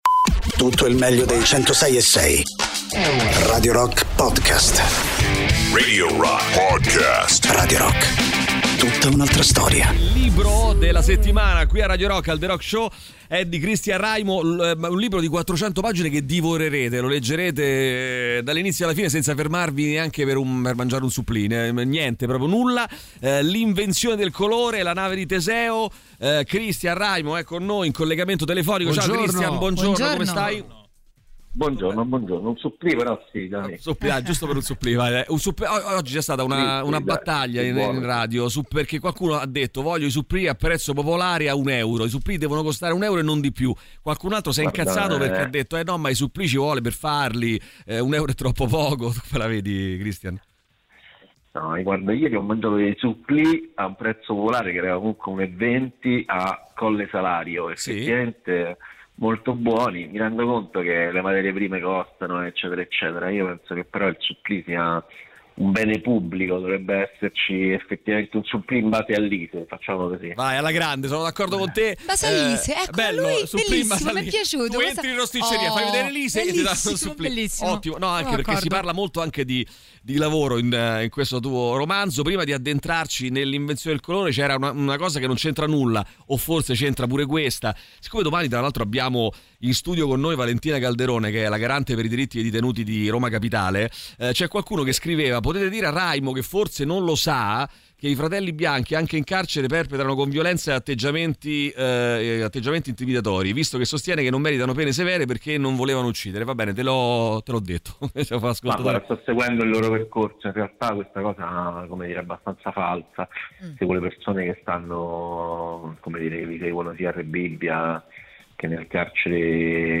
Interviste
scrittore, ospite telefonico